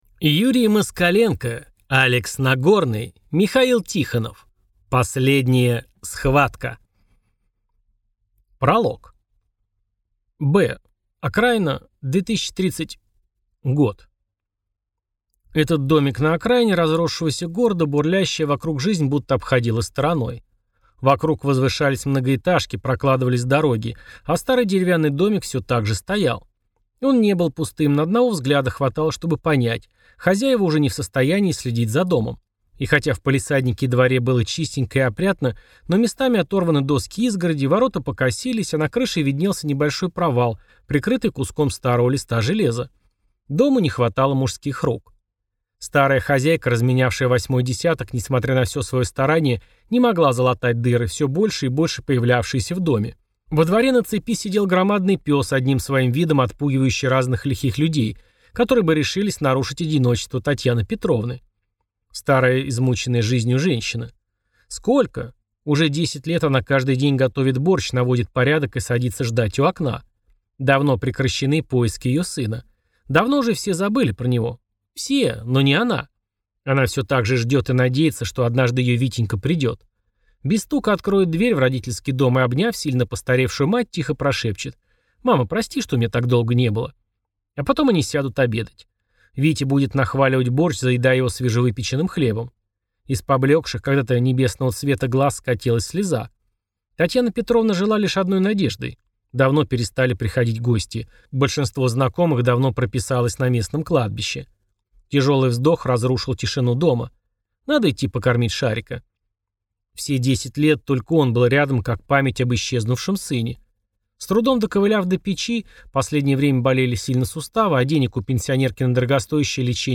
Аудиокнига Последняя схватка | Библиотека аудиокниг
Прослушать и бесплатно скачать фрагмент аудиокниги